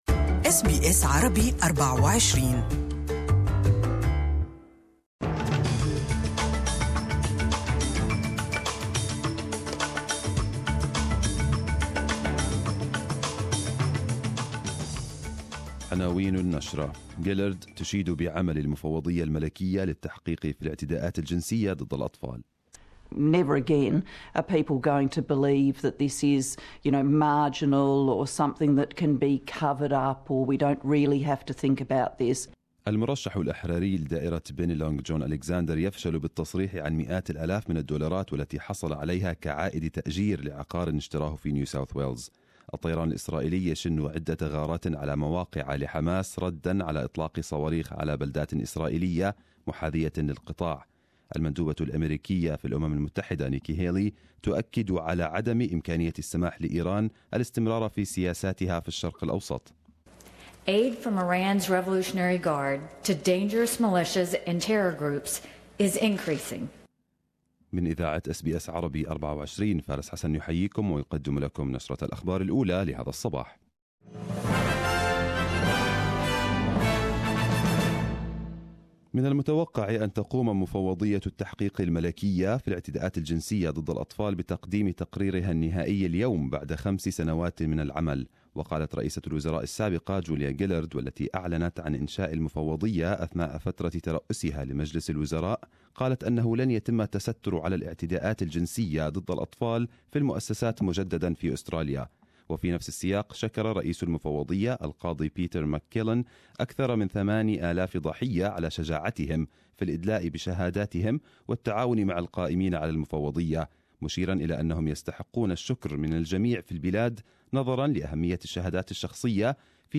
Arabic News Bulletin 15/12/2017